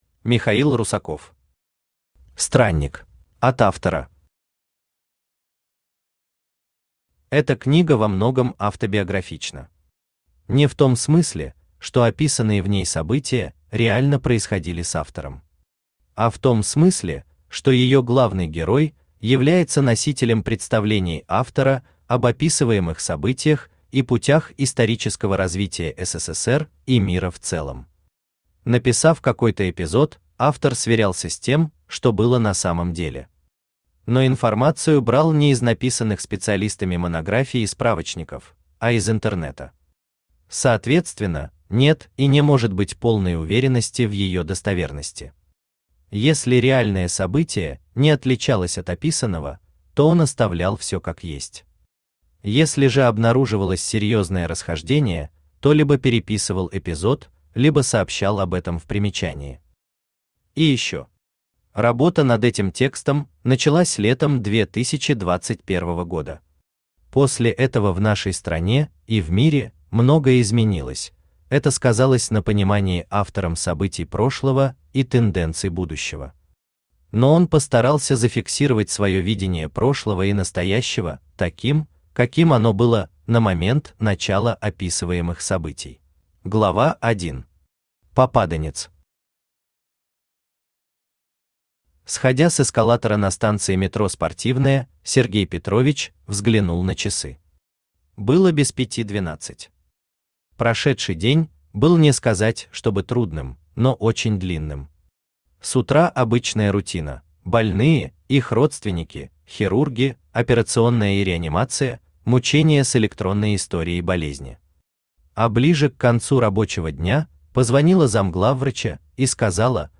Аудиокнига Странник | Библиотека аудиокниг
Aудиокнига Странник Автор Михаил Русаков Читает аудиокнигу Авточтец ЛитРес.